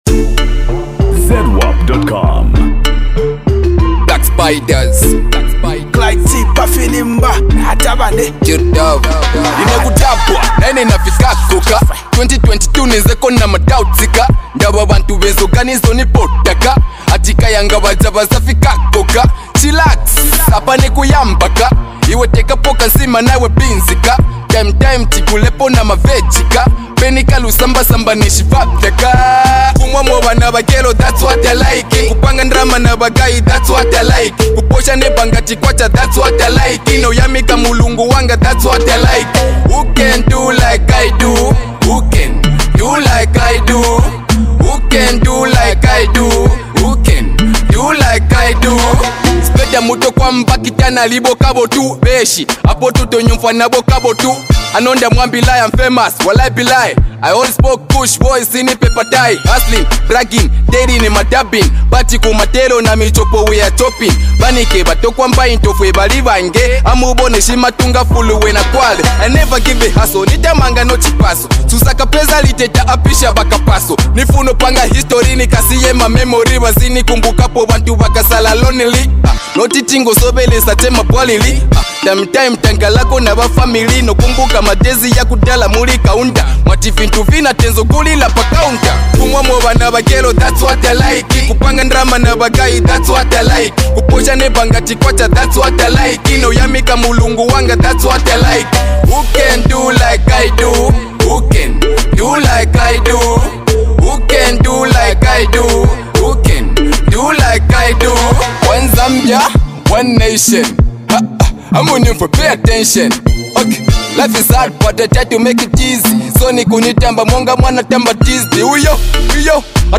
Iconic Zambian rapper